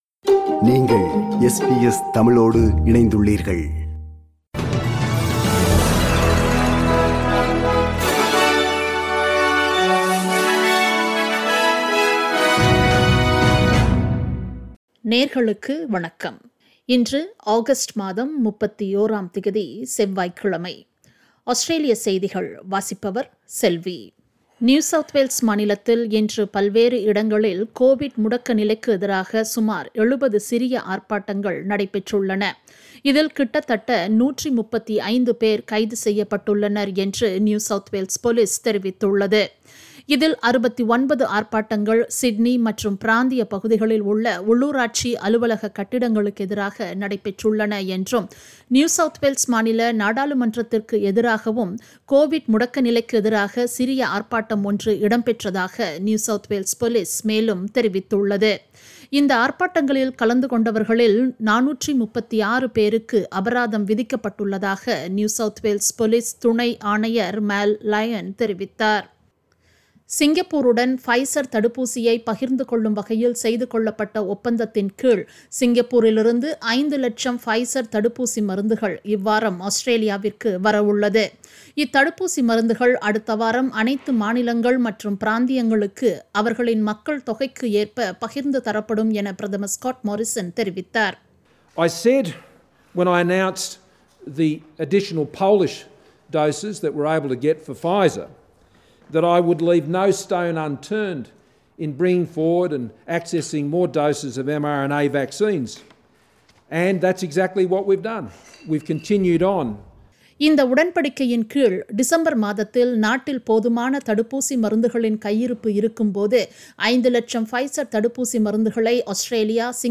Australian news bulletin for Tuesday 31 August 2021.